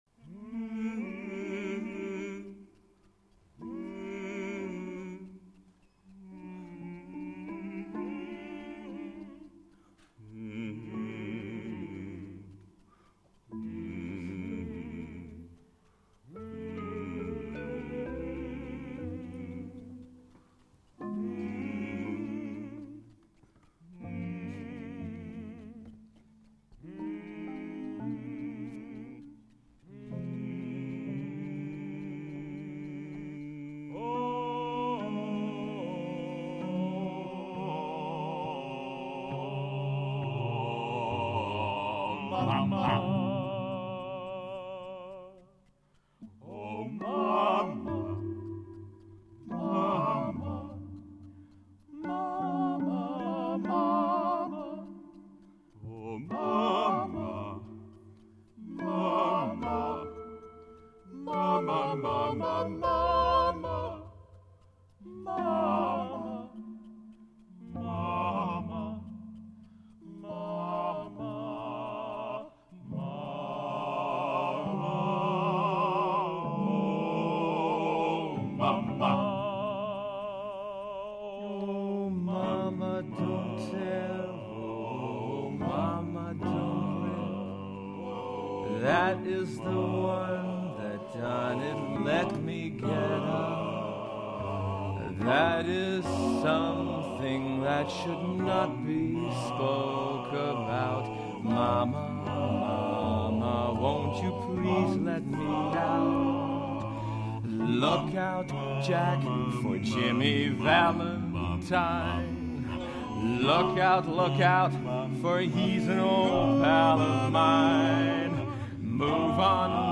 baritone